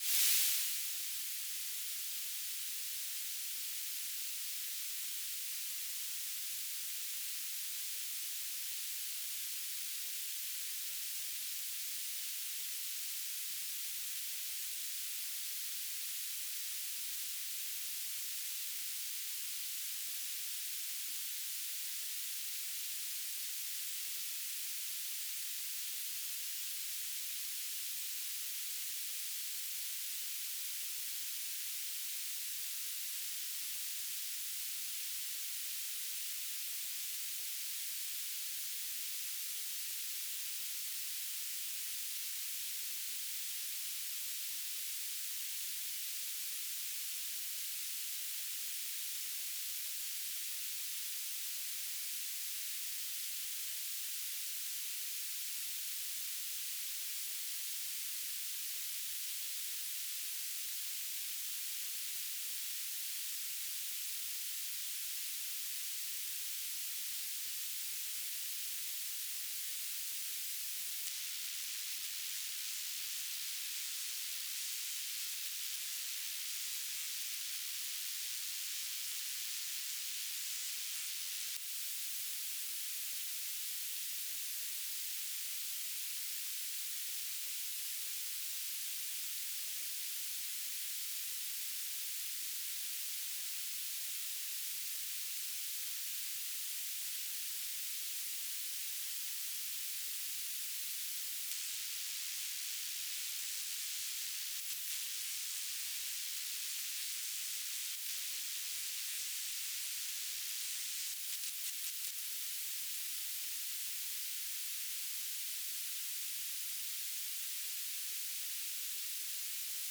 "transmitter_description": "Mode U BPSK9k6 TLM",
"transmitter_mode": "BPSK",